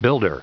Prononciation du mot builder en anglais (fichier audio)
Prononciation du mot : builder